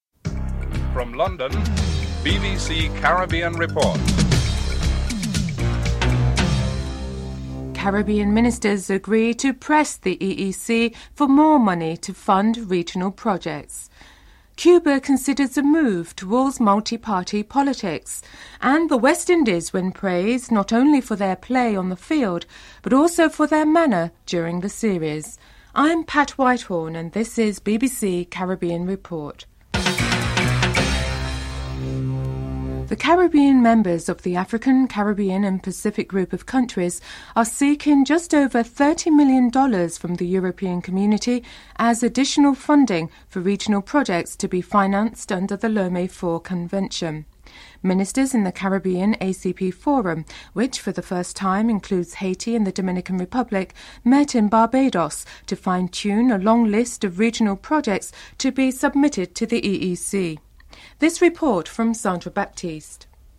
The British Broadcasting Corporation
1. Headlines (00:00-00:32)